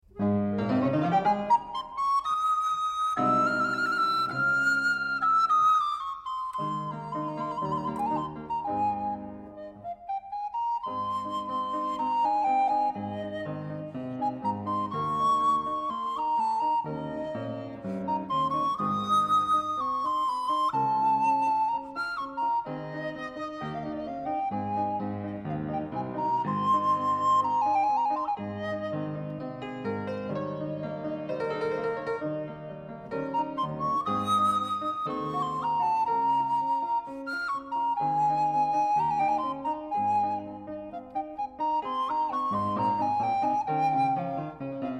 Obsazení: Sopranblockflöte (oder Czakan) und Klavier